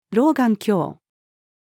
老眼鏡-female.mp3